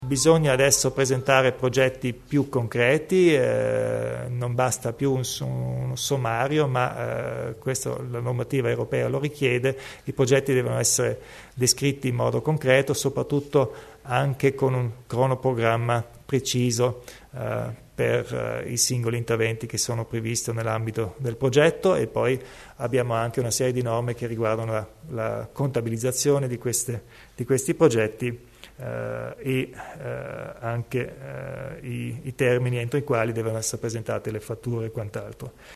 Il Presidente Kompatscher spiega le novità in tema di Export